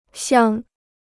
乡 (xiāng): country or countryside; native place.